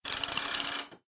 telephone_handle2.ogg